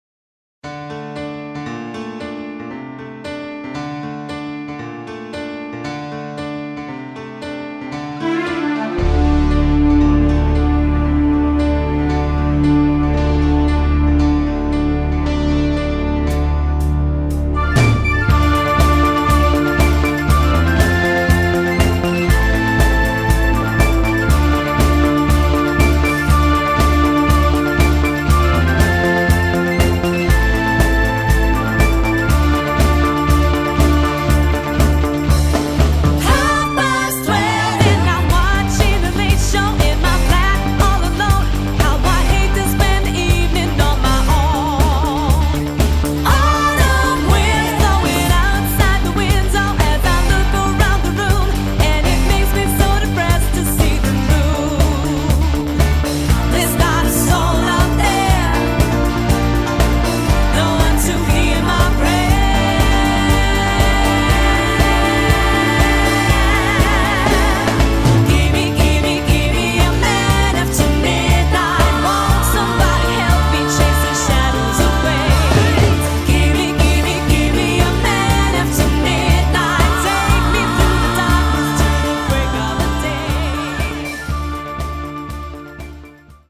Tribute Acts